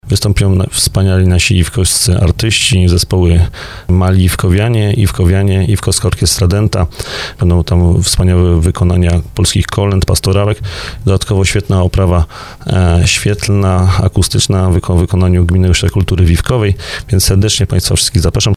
– zapraszał wójt gminy Iwkowa Bartłomiej Durbas.